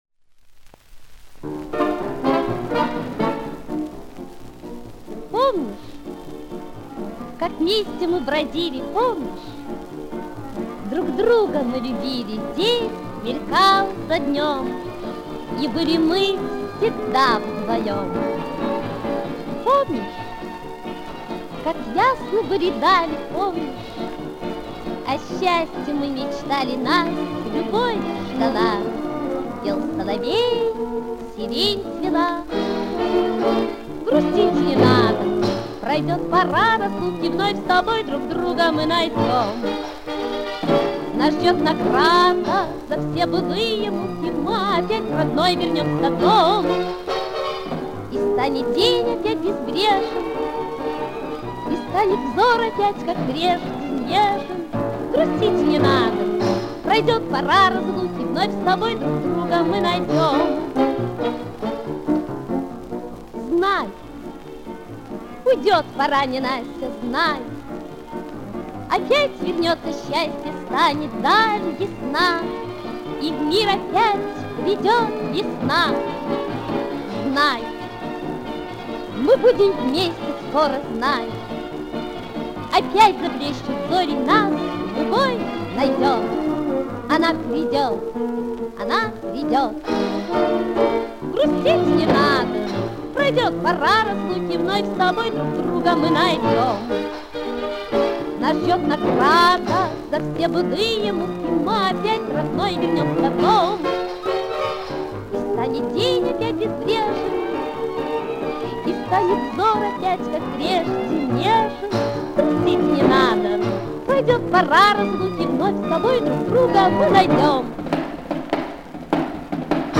Знатоки помогите установить кто поет.